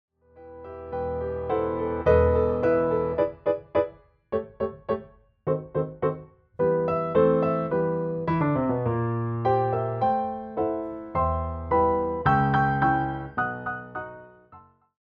adds a brighter touch.